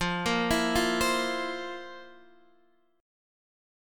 FM7sus4 chord